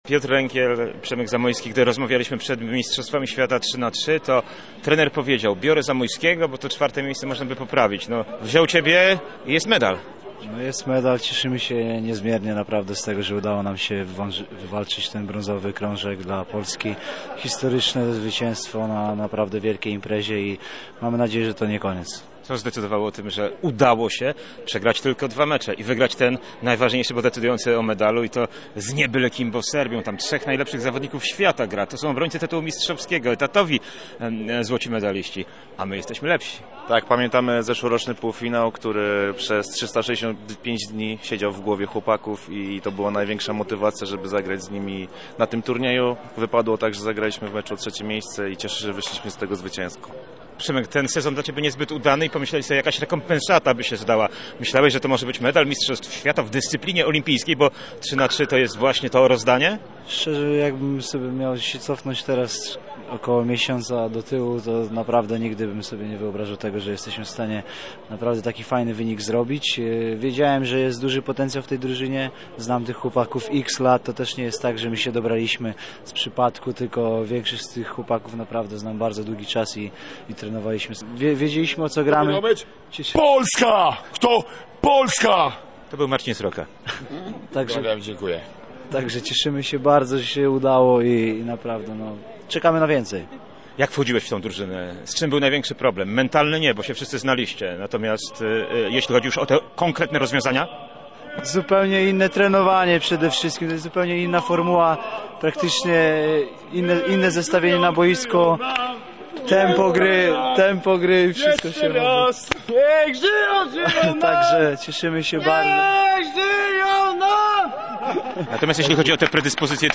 który na Lotnisku im. Lecha Wałęsy witał naszych medalistów.